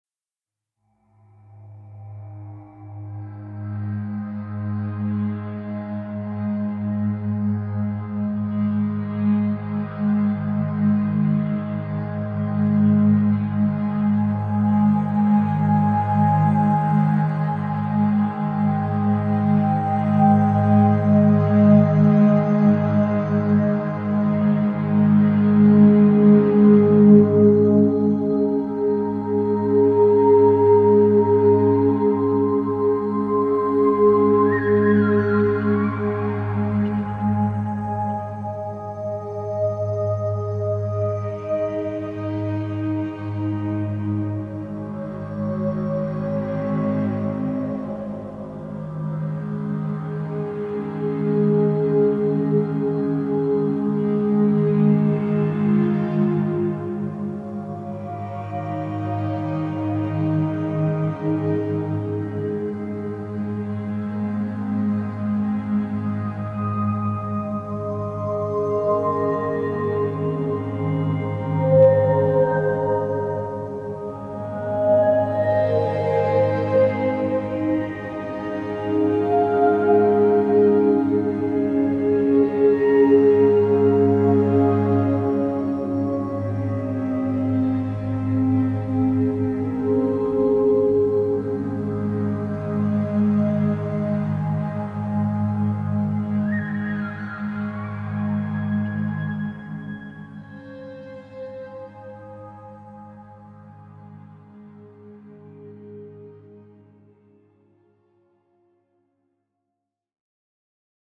Sie erhalten mit dem EINSCHLAFMEISTER eine Mischung von Sounds vorwiegend aus dem Bereich der Isochronen Töne und einem geringen Anteil an Binauralen Beats mit unterschiedlichem Wirkungsziel.